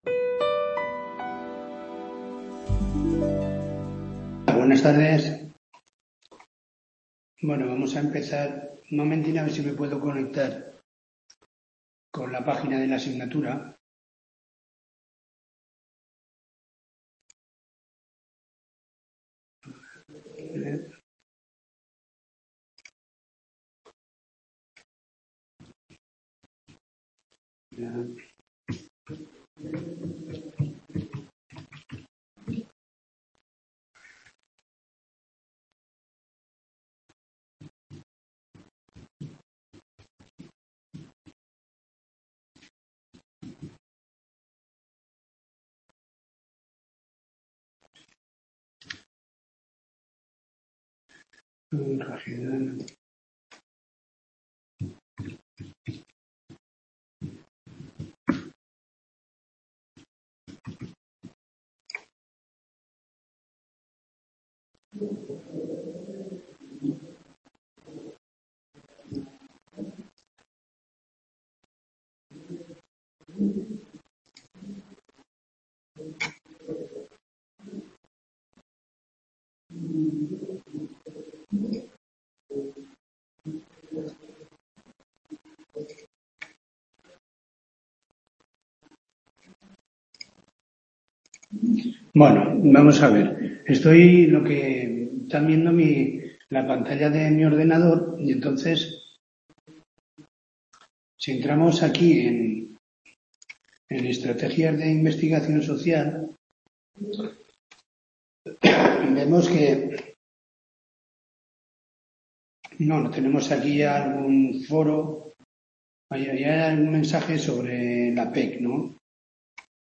TEMA 1 PARTE A TUTORIA DE 15/02/2023